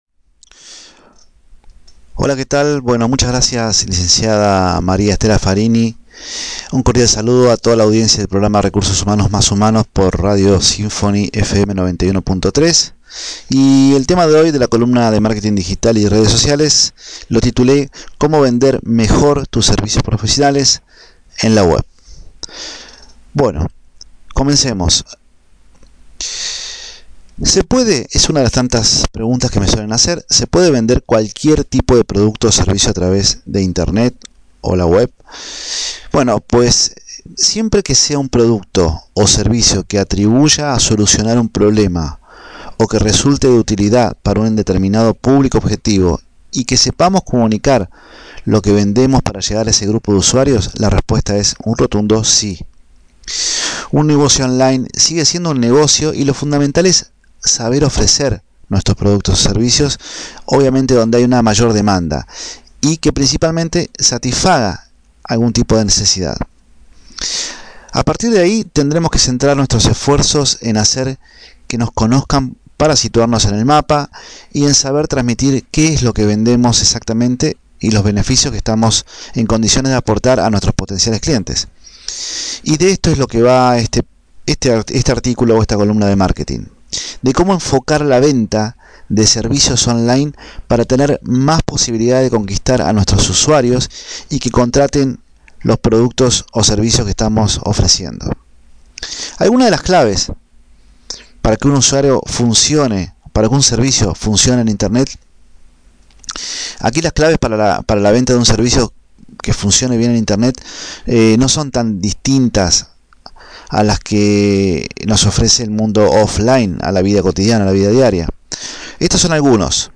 Audio Radial: Como Vender Mejor Tus Servicios Profesionales en la Web